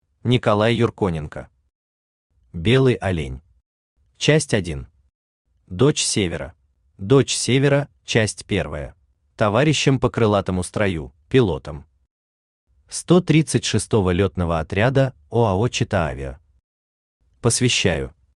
Аудиокнига Белый олень. Часть 1. Дочь севера | Библиотека аудиокниг
Дочь севера Автор Николай Александрович Юрконенко Читает аудиокнигу Авточтец ЛитРес.